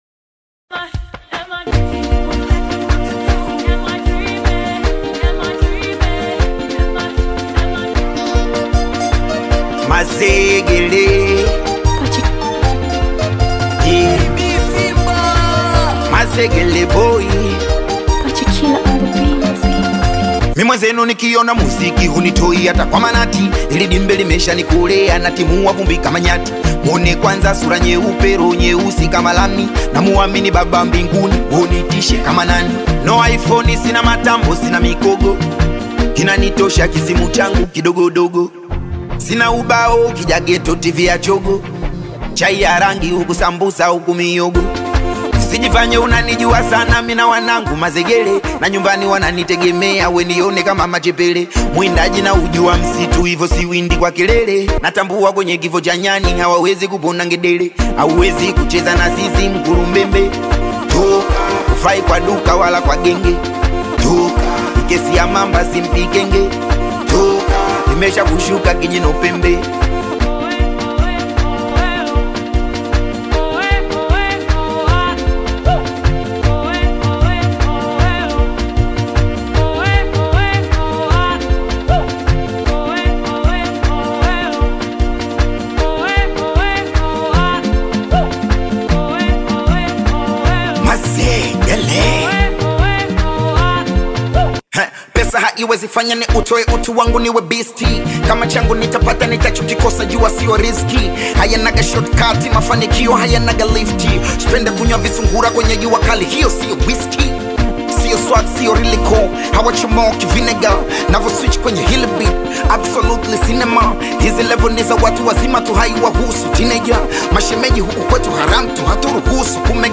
Tanzanian Singeli
street anthem